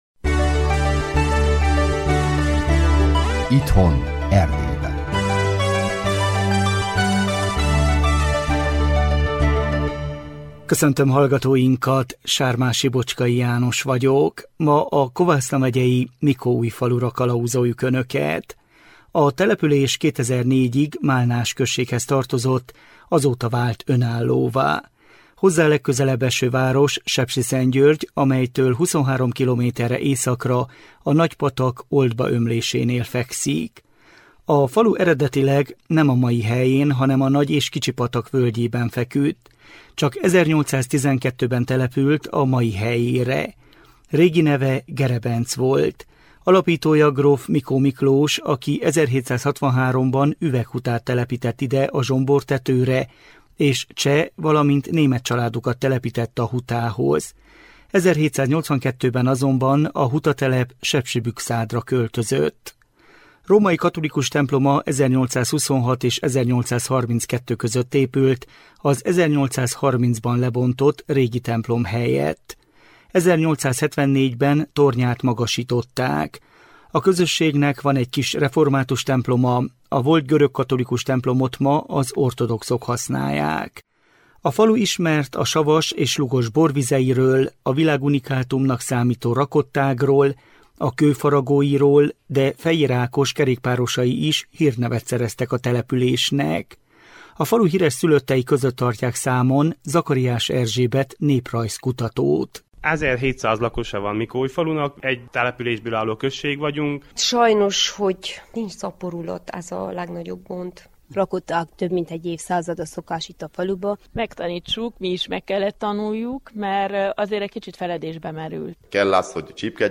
Összeállításunk második részét sugározzuk.